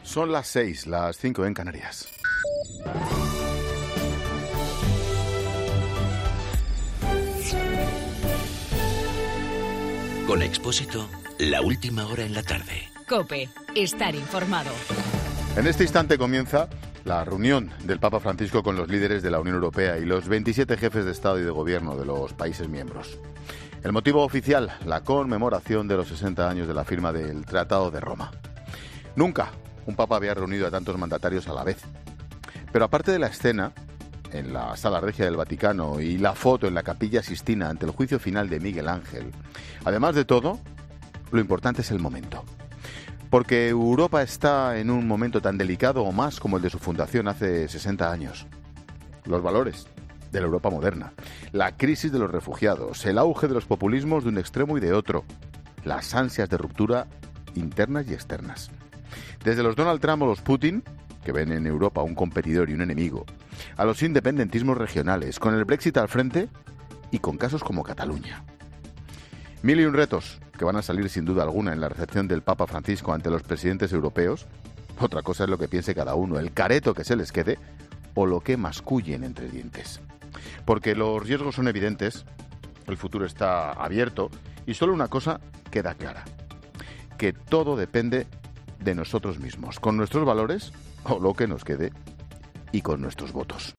AUDIO: Monólogo 18.00